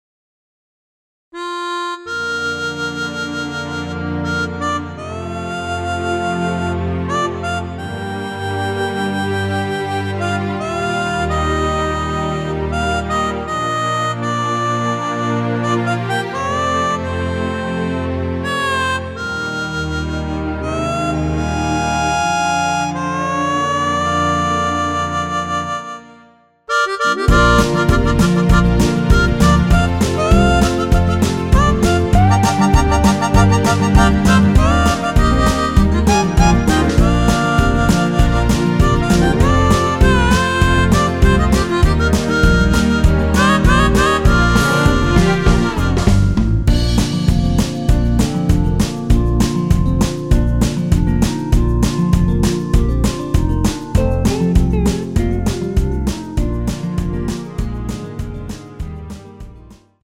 원키에서(-2)내린 멜로디 포함된 MR 입니다.(미리듣기 확인)
Bb
앞부분30초, 뒷부분30초씩 편집해서 올려 드리고 있습니다.
중간에 음이 끈어지고 다시 나오는 이유는